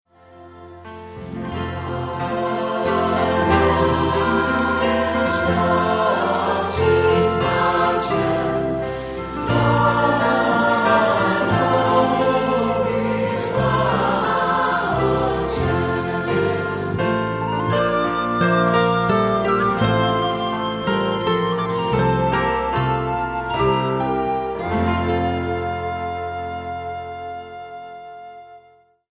Accompaniment:      Keyboard, C Instrument I;C Instrument II
Music Category:      Christian